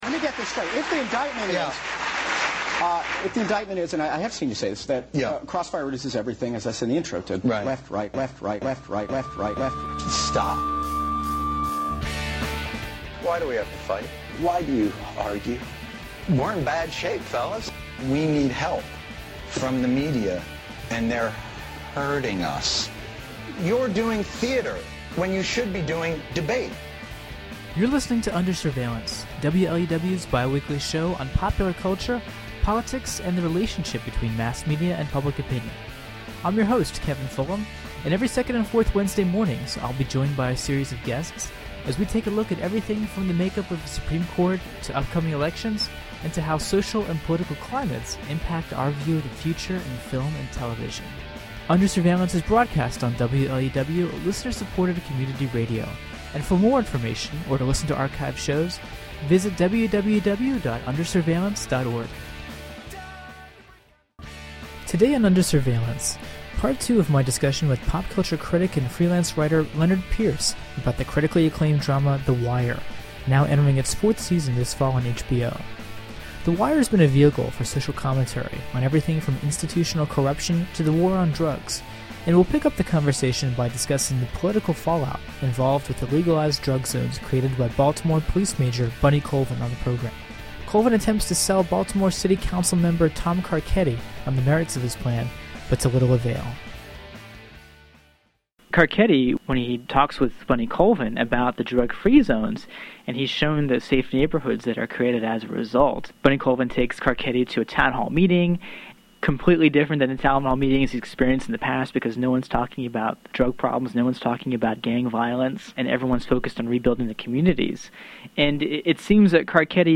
[Originally broadcast on WLUW’sÂ Under Surveillance in 2006.]